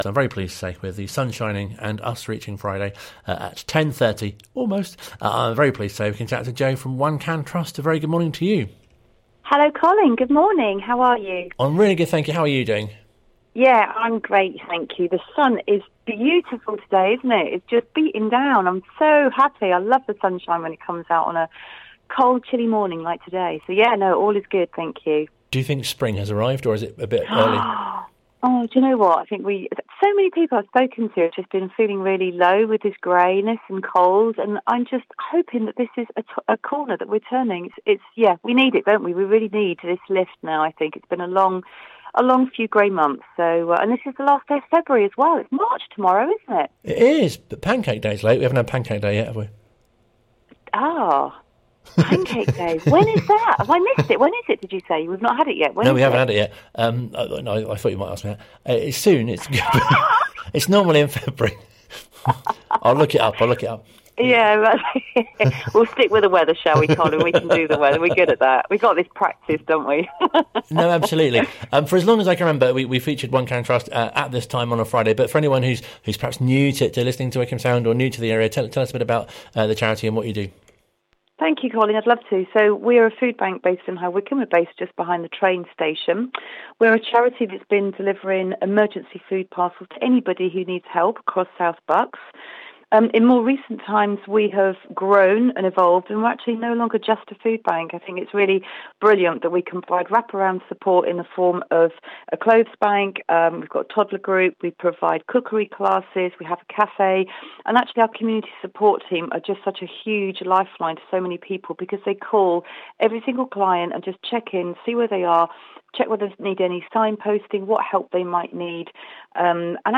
One Can Trust interview